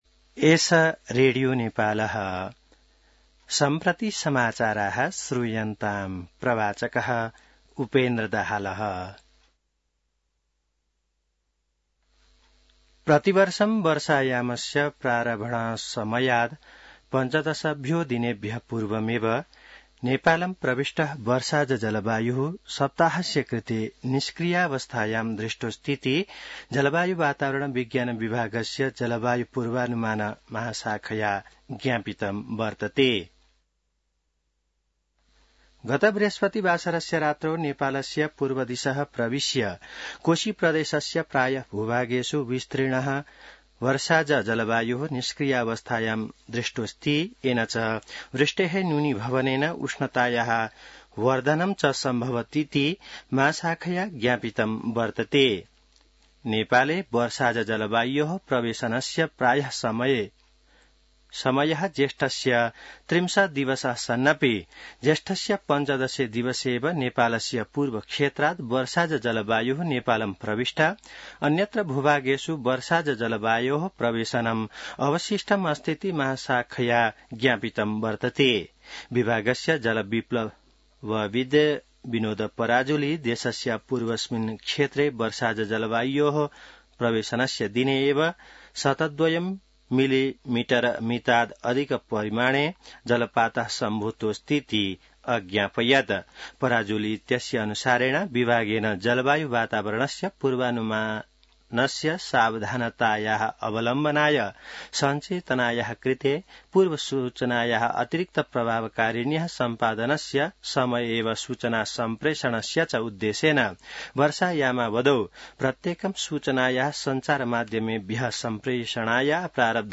संस्कृत समाचार : १९ जेठ , २०८२